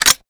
weapon_foley_drop_18.wav